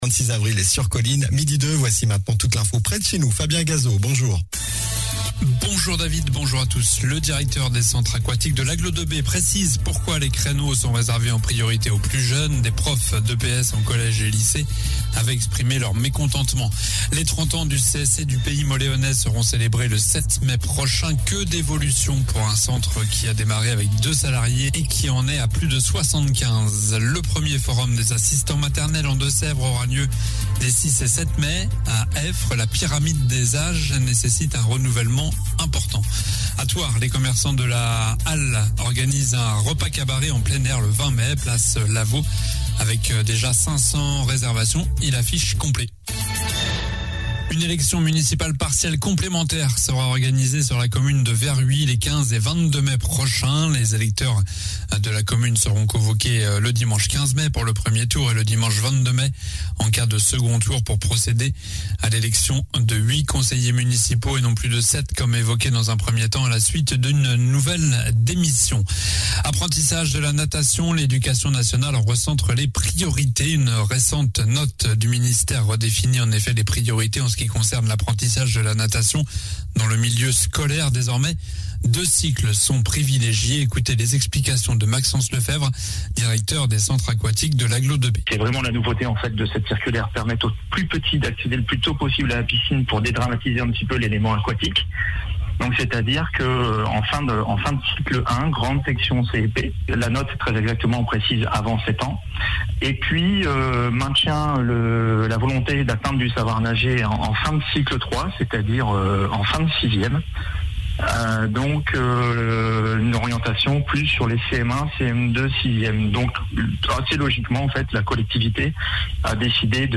Journal du mardi 26 avril (midi)